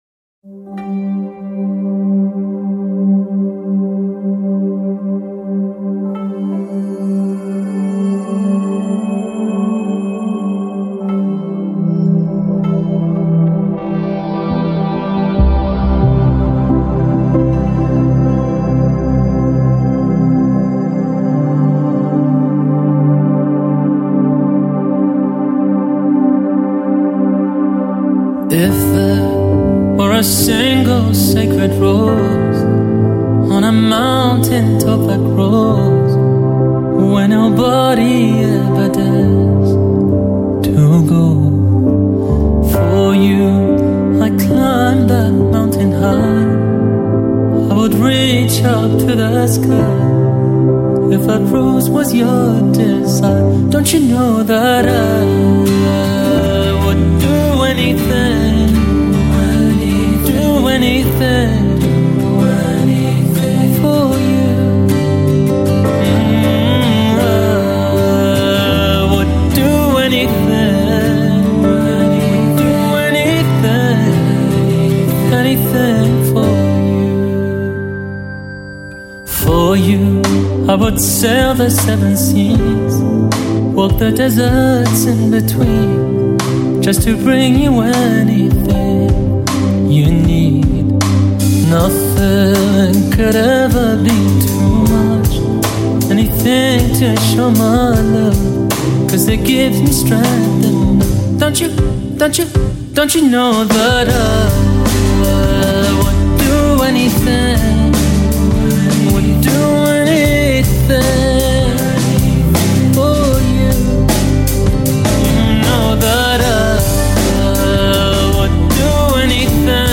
Hamd Naat & Nasheeds [Arabic & English]